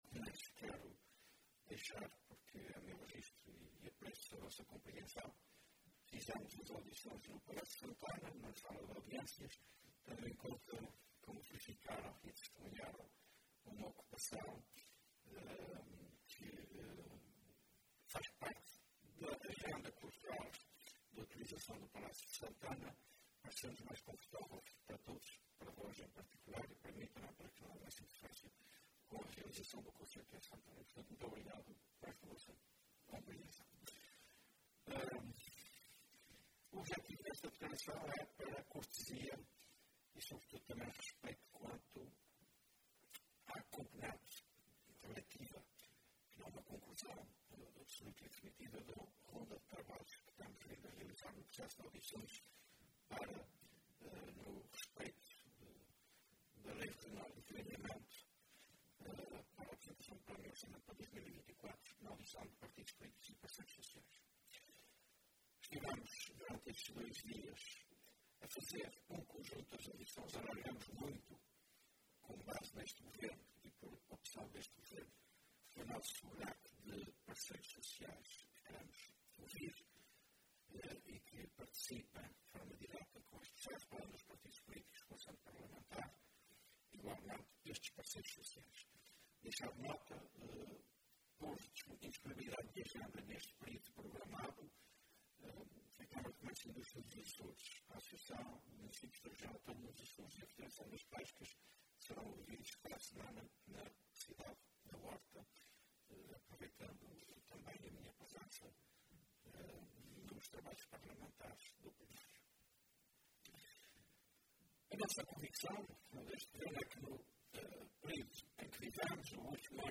Falando no final de dois dias de auscultações com partidos e parceiros sociais sobre a preparação da anteproposta de Plano e Orçamento, o Presidente do Governo, que assume o papel de referencial de estabilidade, lembrou os prazos dos quadros comunitários com aplicação na região, em concreto o Plano de Recuperação e Resiliência (PRR), de execução até 2026.